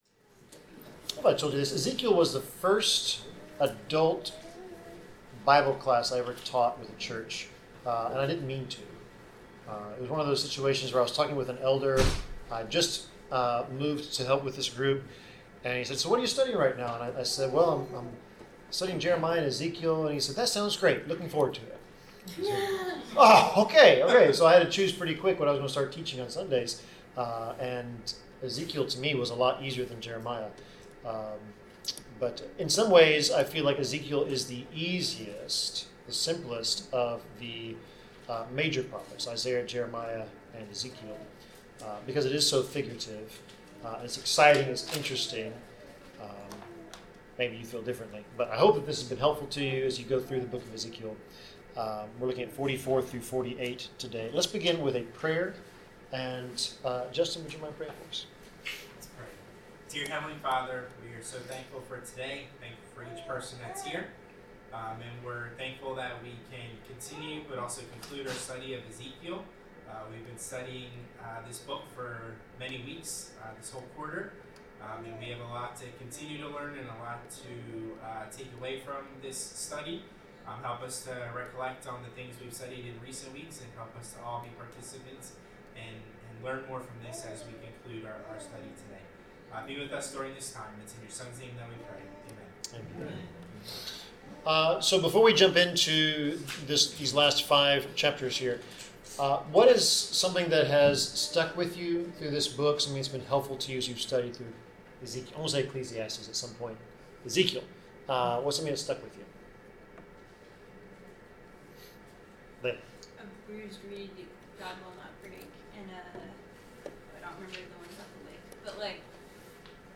Bible class: Ezekiel 44-48
Service Type: Bible Class